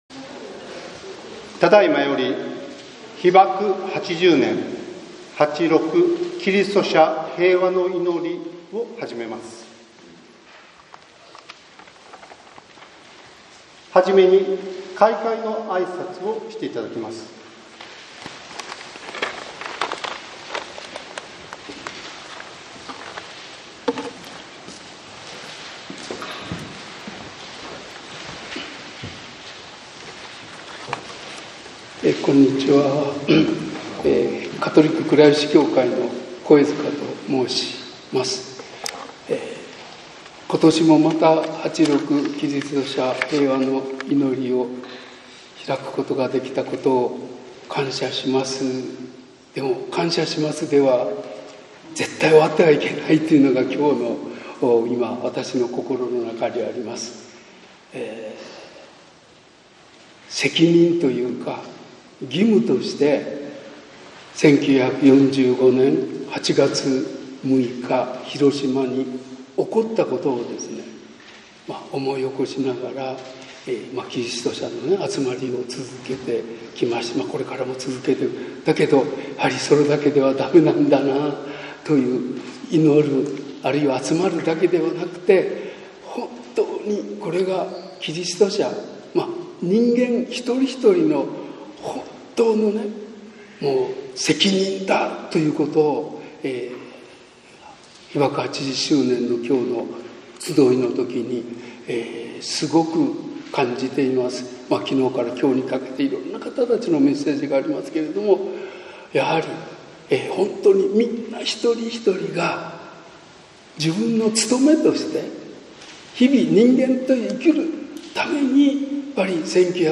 被爆80年8･6キリスト者平和の祈り(2025年8月6日午後3時～)